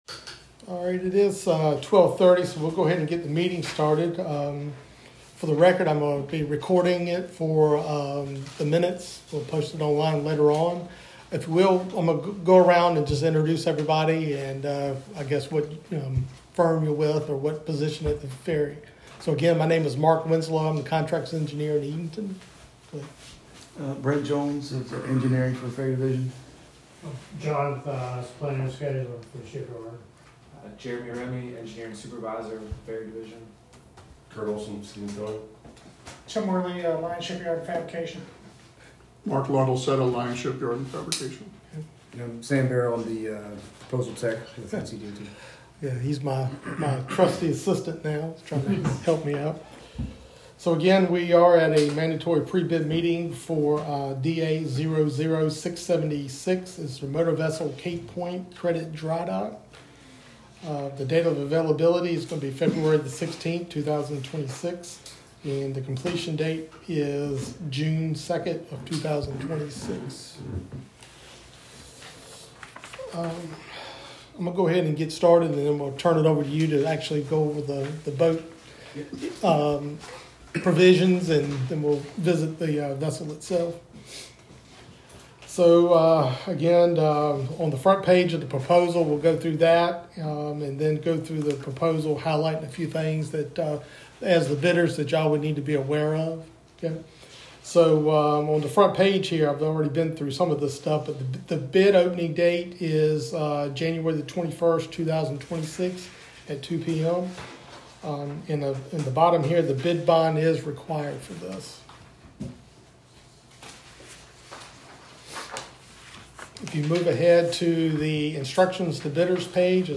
DA00676 Pre-Bid Meeting Recording.m4a